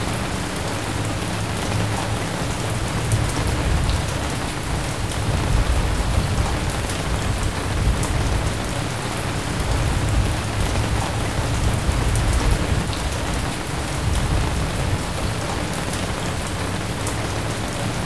Heavy Rain 4.wav